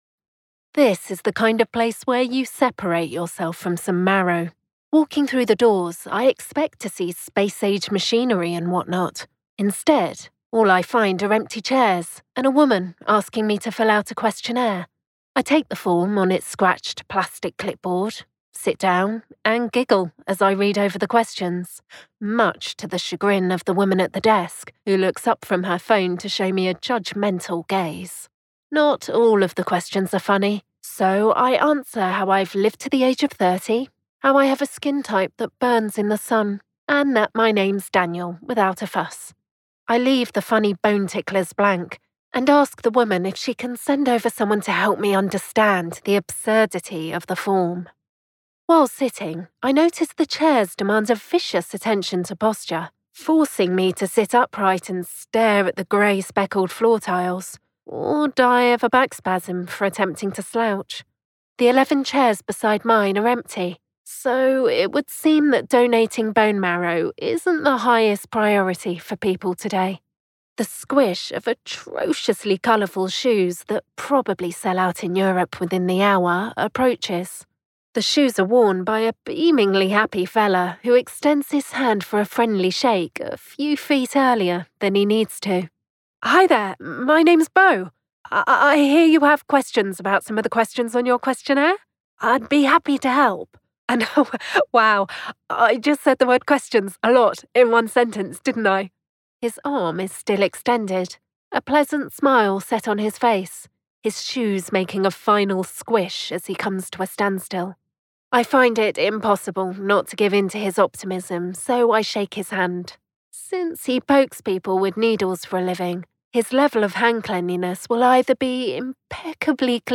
british, female